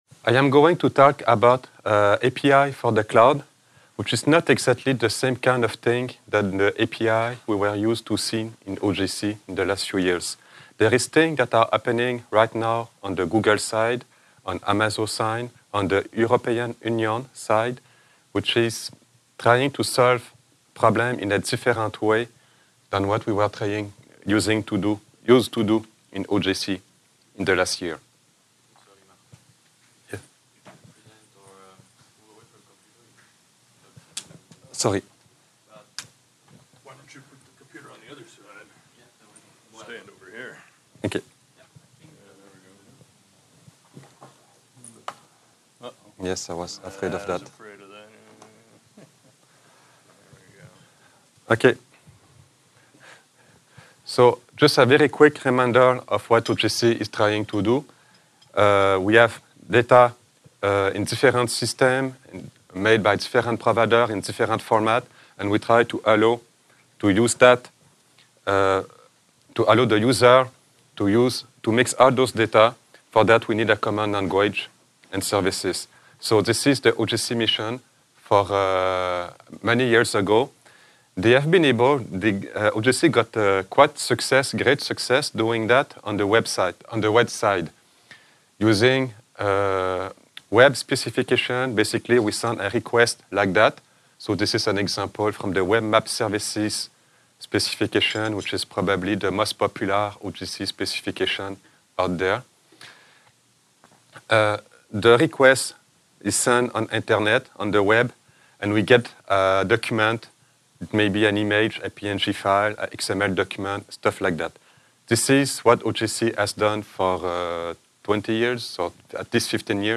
Tags: ApacheCon, apacheconNA2018, Podcasts • Permalink